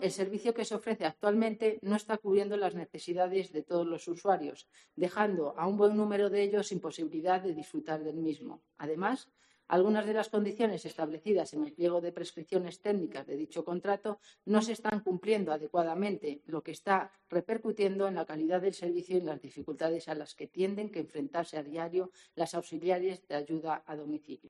Eva Arias, portavoz PSOE. Moción Ayuda a Domicilio